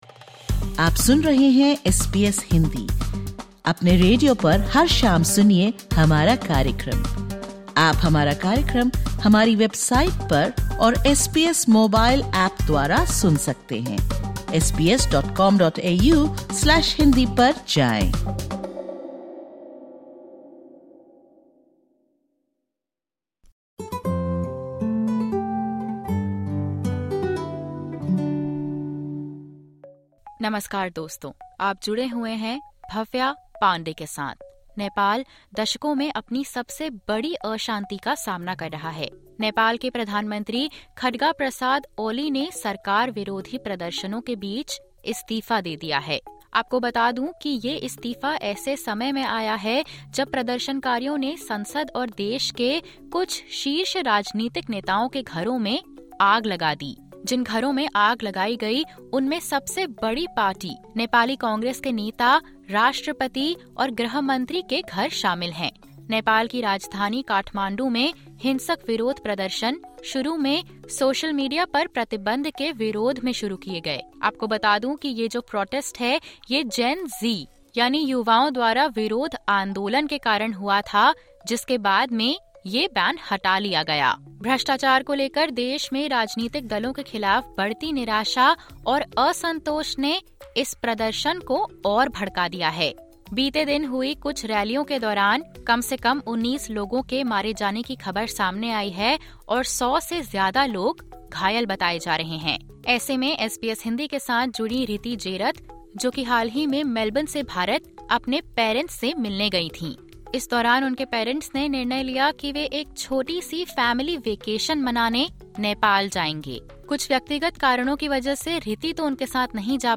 Sadness, uncertainty, and hope echo through the voices of Nepali Australians as their homeland faces a crisis.